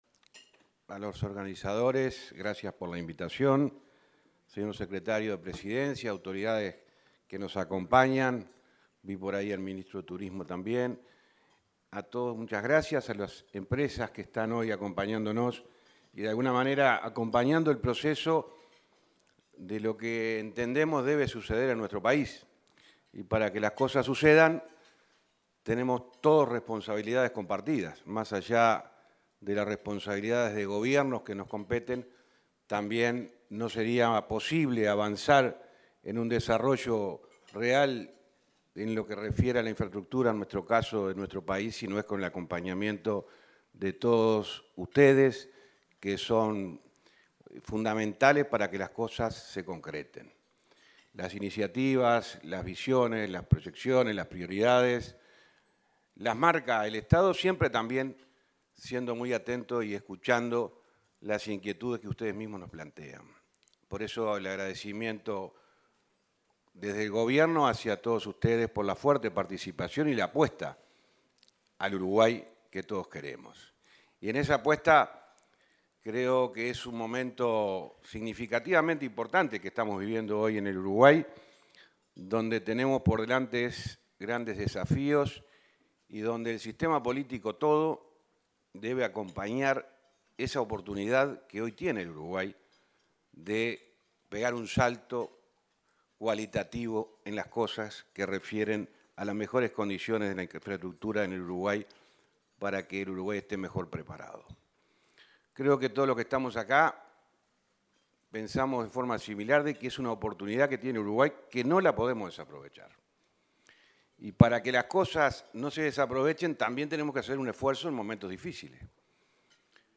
Palabras del ministro de Transporte y Obras Públicas, José Luis Falero
Palabras del ministro de Transporte y Obras Públicas, José Luis Falero 17/08/2022 Compartir Facebook X Copiar enlace WhatsApp LinkedIn El ministro de Transporte y Obras Públicas, José Luis Falero, participó en la actividad organizada por Somos Uruguay, denominada Las Obras de Infraestructura Viales, Ferroviarias y Portuarias del Gobierno 2020-2025, este 17 de agosto.